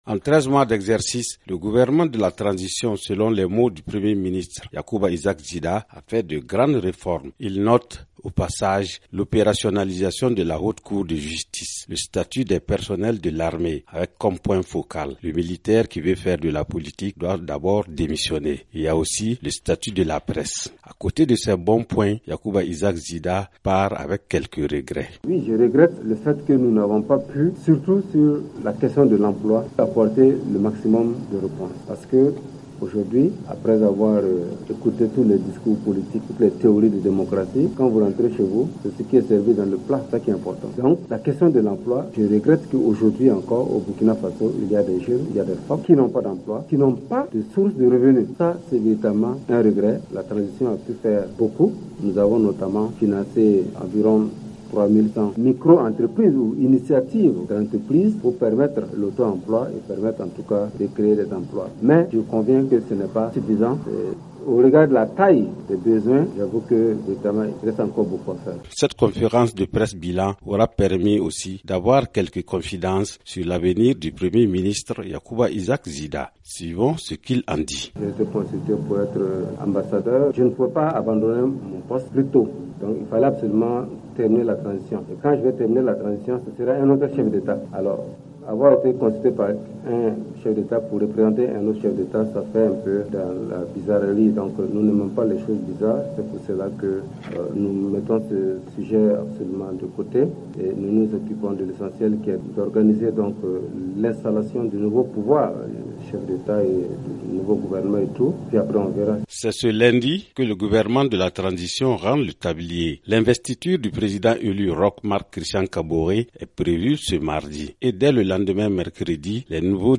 Reportage de notre correspondant à Ouagadougou sur la conférence de presse de Yacouba Isaac Zida.